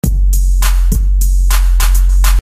TR808环路2
描述：TR808，鼓组，低音炮
Tag: 102 bpm Hip Hop Loops Drum Loops 405.38 KB wav Key : Unknown